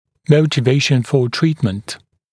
[ˌməutɪ’veɪʃn fə ‘triːtmənt][ˌмоути’вэйшн фо ‘три:тмэнт]мотивация к лечению